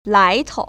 [lái‧tou] 라이토우  ▶